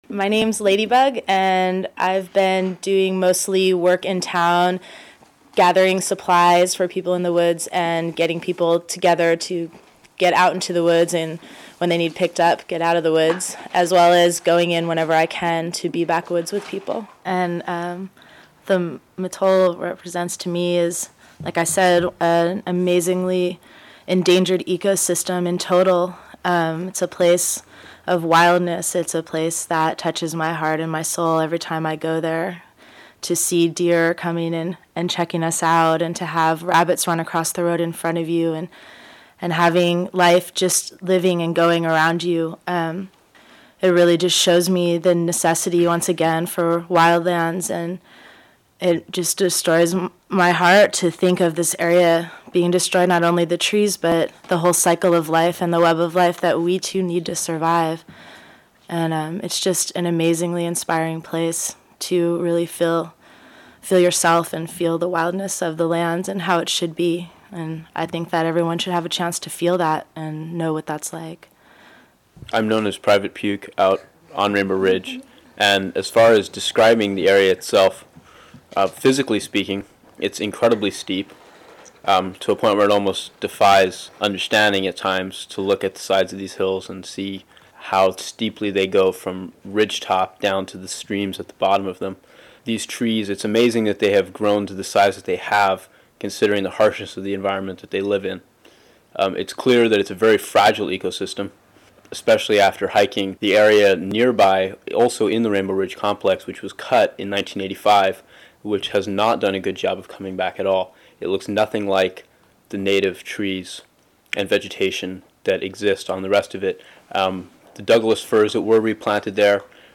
Hear the voices of the Mattole Forest Defenders, as they introduce themselves and describe the struggle to defend some of the last of the ancient Douglas Fir forests from plunder by Maxxam/ Pacific Lumber Company. The activists describe the area, confrontations with police and logging crews, pepper spray and arrests, and the complex blockades they have built twelve miles into PL company land to keep the trees standing, with contact information at the end. Community and Micro radio producers are encouraged to rebroadcast this collage of voices from the front lines!